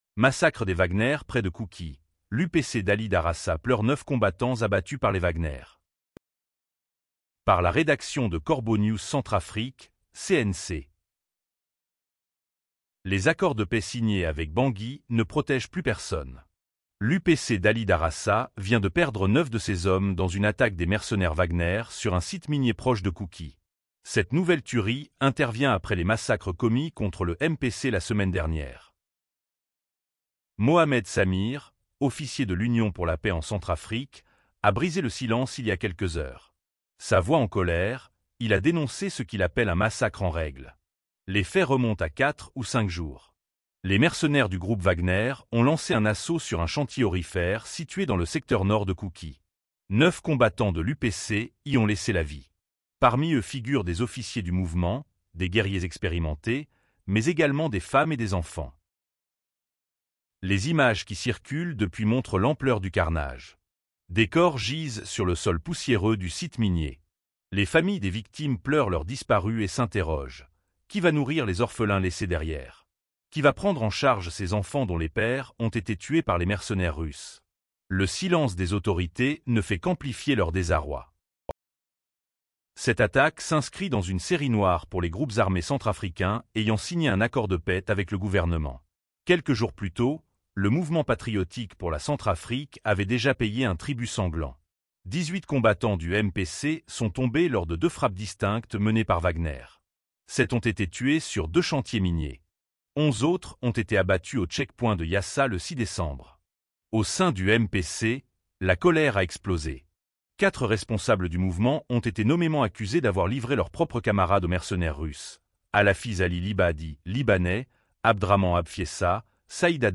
Sa voix en colère, il a dénoncé ce qu’il appelle un massacre en règle.